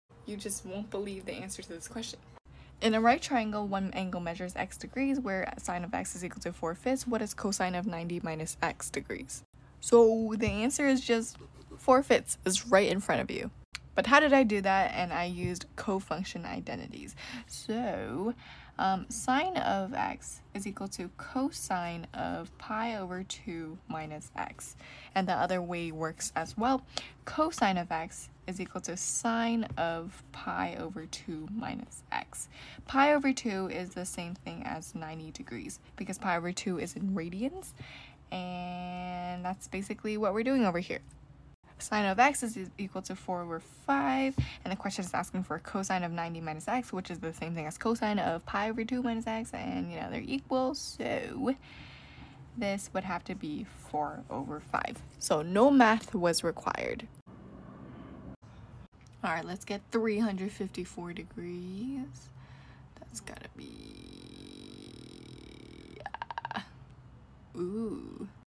Why did i sound like a dinosaur at the end